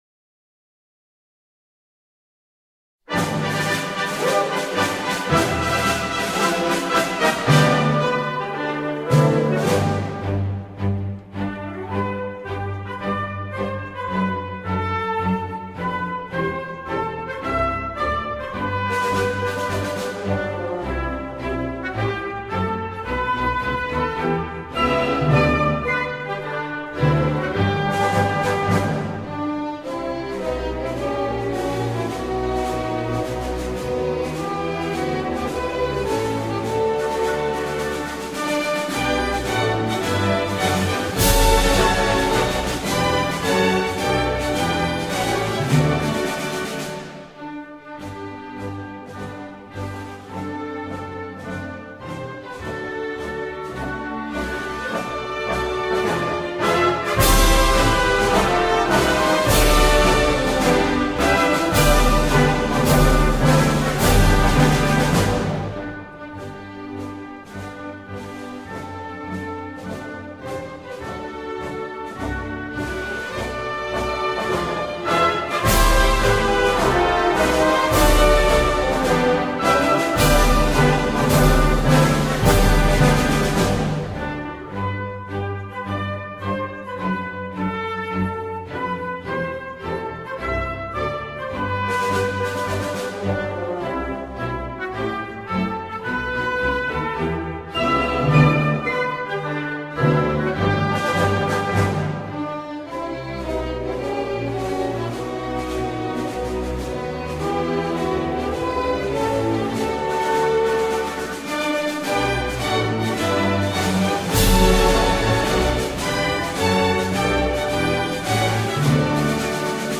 軍歌版本